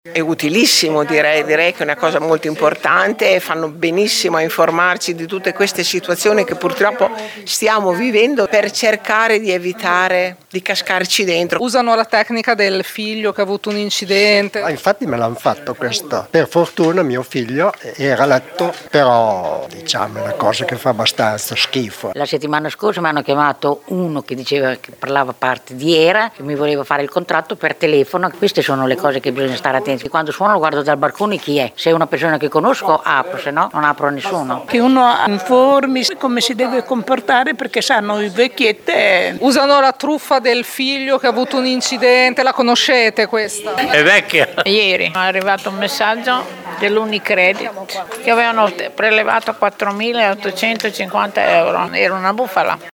Il primo incontro si è svolto ieri pomeriggio al Circolo XXII Aprile, alla Sacca.
Qui sotto alcune interviste